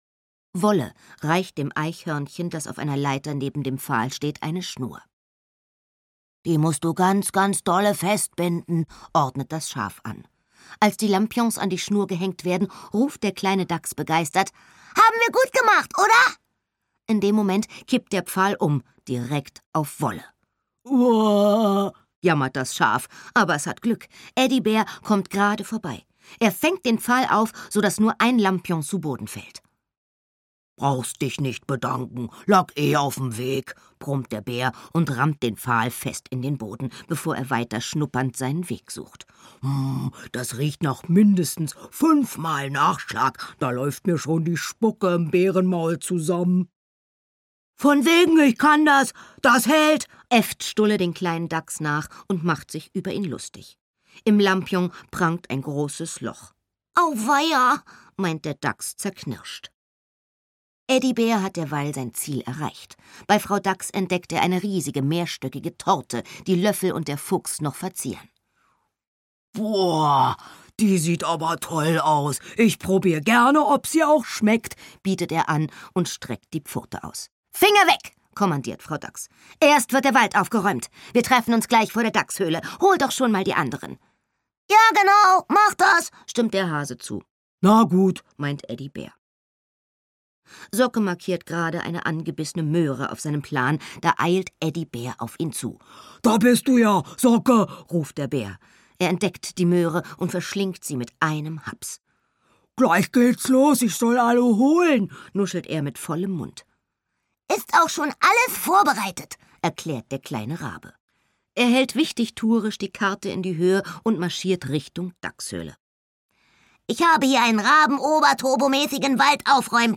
Suche nach dem verlorenen Schatz (Der kleine Rabe Socke) - Nele Moost - Hörbuch